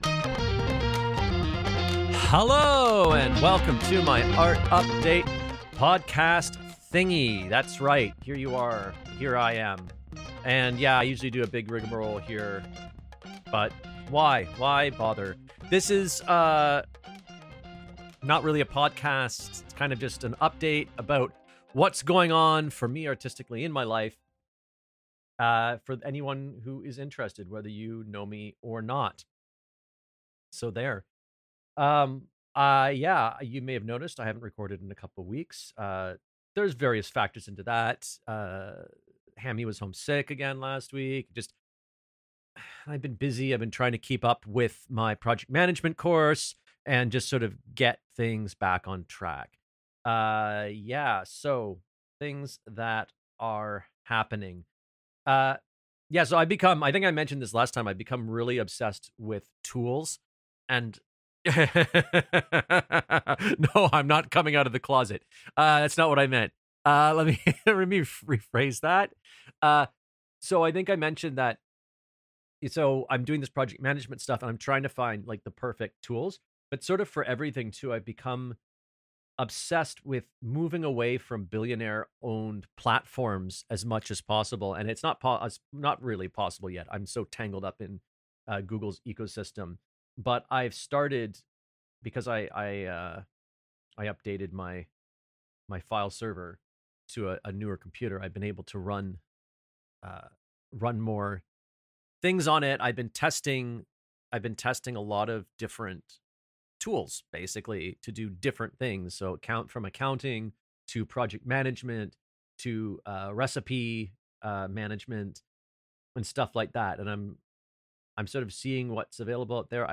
Sorry, didn't script this one.